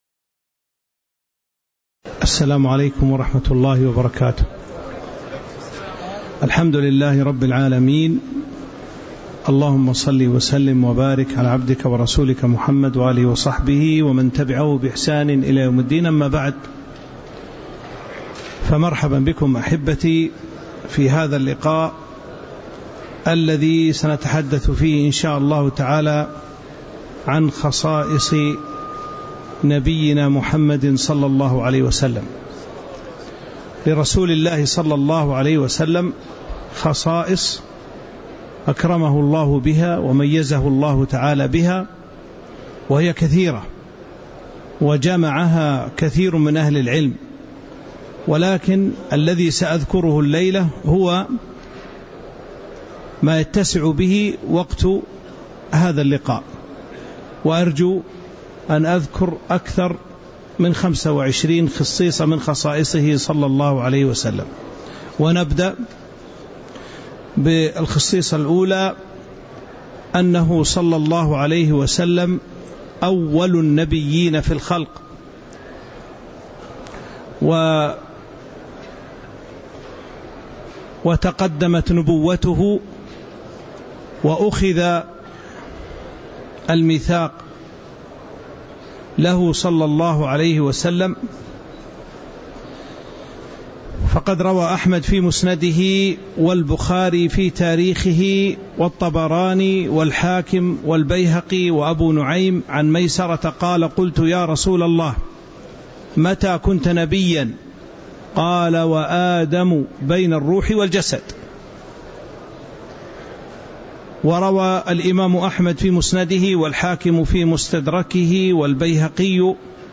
تاريخ النشر ١٩ ذو الحجة ١٤٤٥ هـ المكان: المسجد النبوي الشيخ